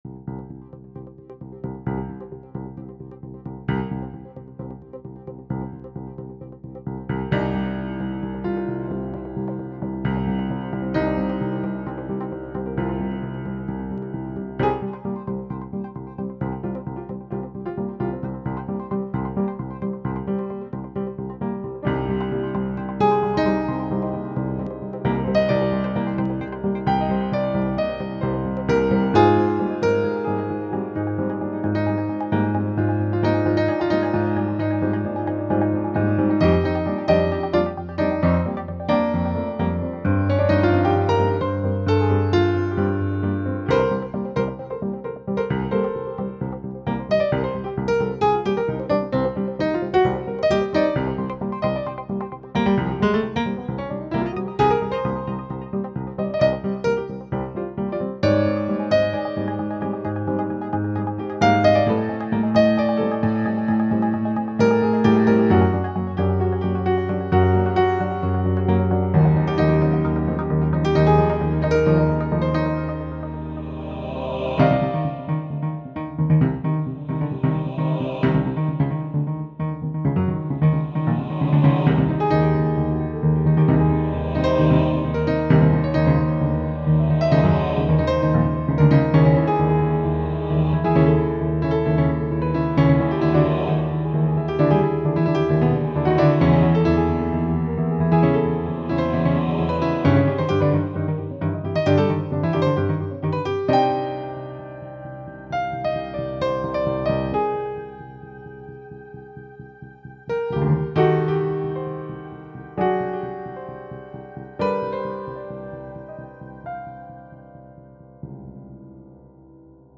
alors là on est dans le vif, tension permanente avec ces solis, envolés "notatoires" et groove bien pensé.
le 2 aussi parce que cette ambiance jazzy et les solos m'ont séduit.
alors là on change complètement de registre par rapport à la première, on est plus dans un aspect jazzy moderne avec un beau jeu au piano
la basse marque de suite le rythme de cette journée, le jeu du piano improvise et marque le déroulement du travail d'une manière effréné
apparition des voix a 1'13 mais je ne vois pas pourquoi